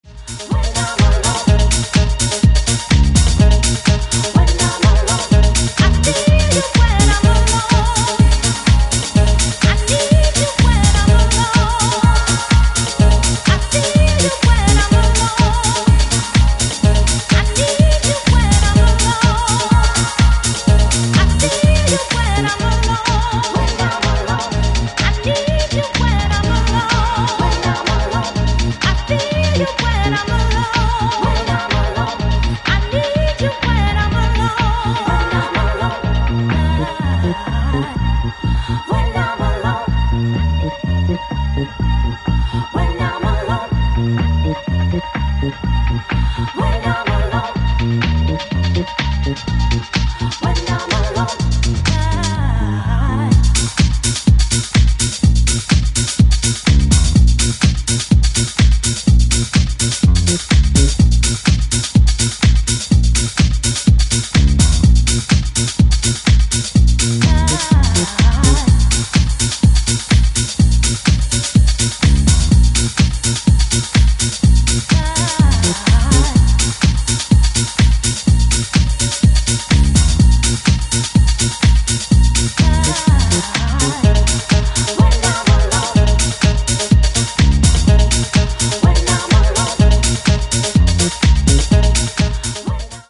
ジャンル(スタイル) NU DISCO / DISCO / HOUSE / RE-EDIT